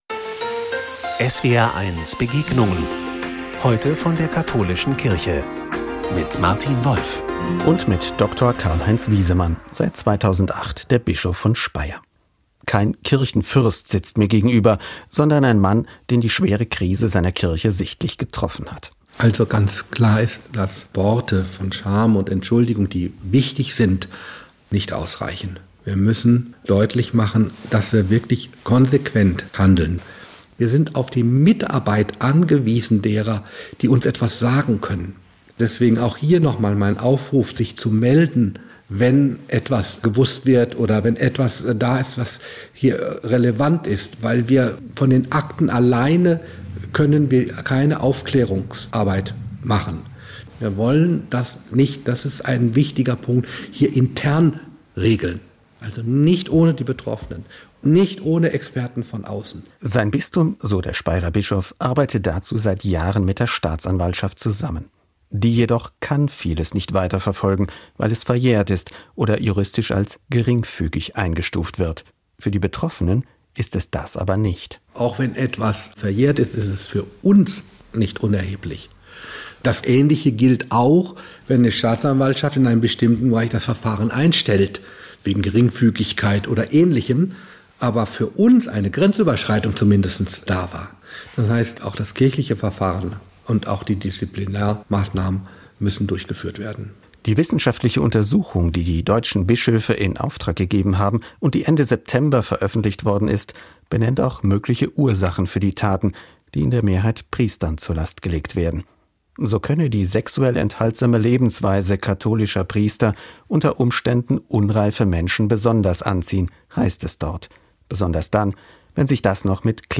Gespräch Teil 1